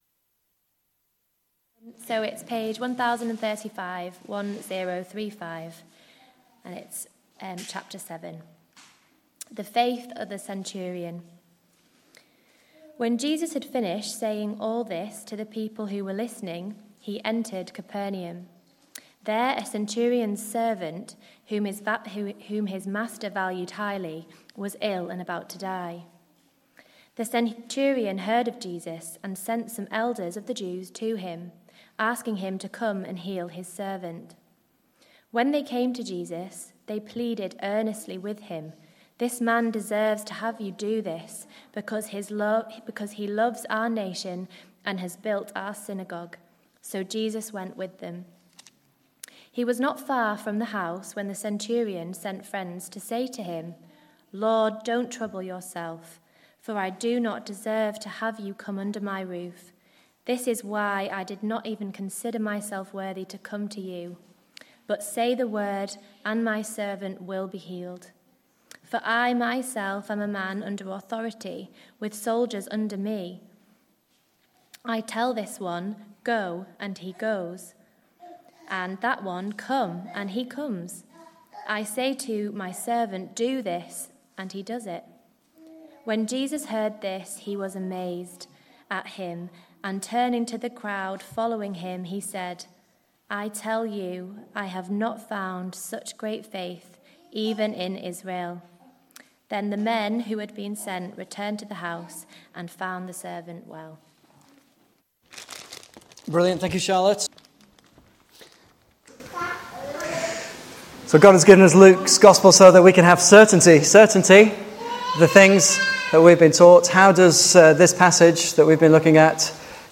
Service Type: Morning Service 11:15